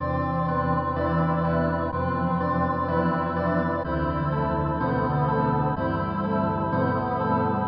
描述：醇厚。
Tag: 125 bpm Chill Out Loops Drum Loops 1.29 MB wav Key : Unknown